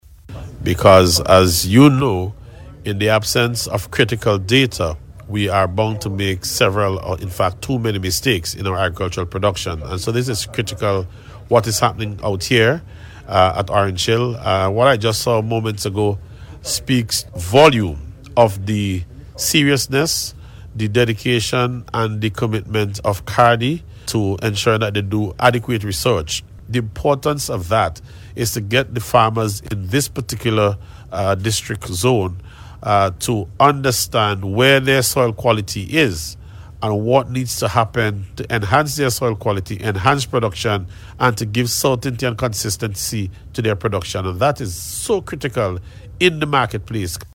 The Minister was speaking to reporters during a visit to the Orange Hill Field Station of the Caribbean Agricultural Research and Development Institute to coincide with activities to observe CARDI Day.